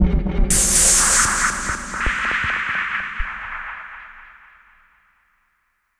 Impact 18.wav